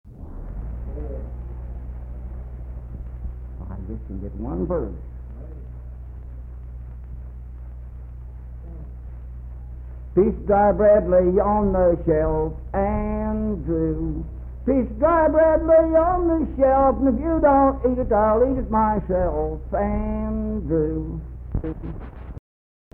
Unaccompanied vocal music performance
Voice (sung)
Spencer (W. Va.), Roane County (W. Va.)